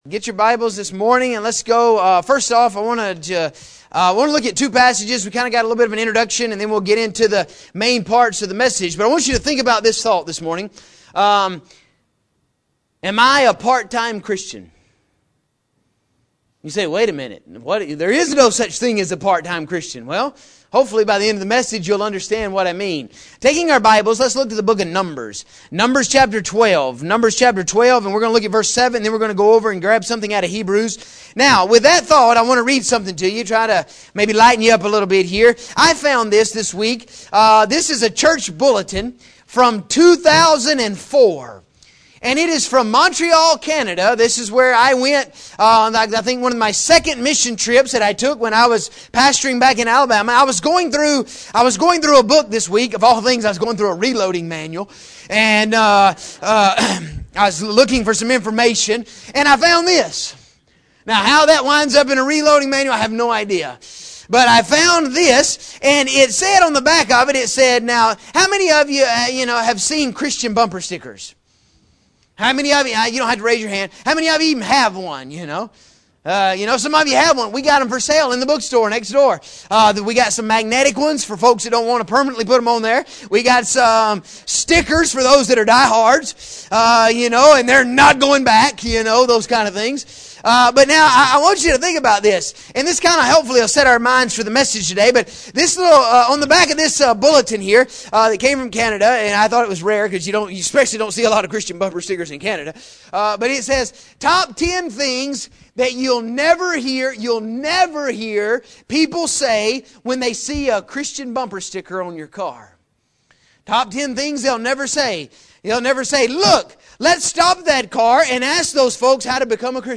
In this sermon we are going to look at what it means to be a part time Christian.